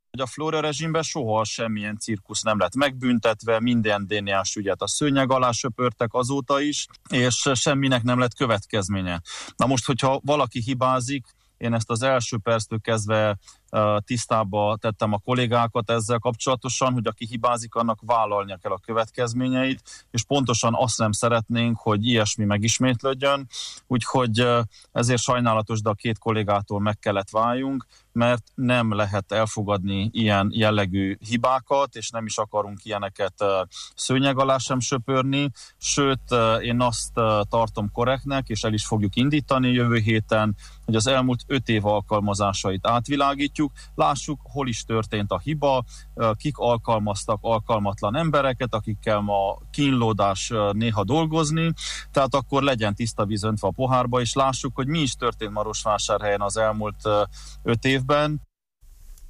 Kezdeményezni fogja az elmúlt öt év alkalmazásainak kiértékelését a marosvásárhelyi városházán Soós Zoltán polgármester, aki az Erdélyi Magyar Televízió tegnap esti, 24plusz című műsorában beszélt a napokban nyilvánosságra kerülő politikai alapú alkalmazások következményeiről.